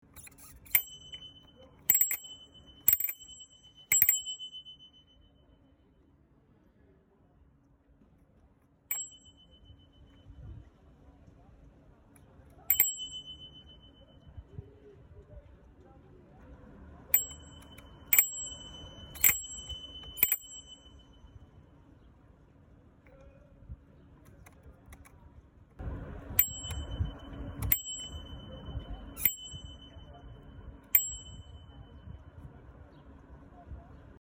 10. Звонок на велосипеде взятом в аренду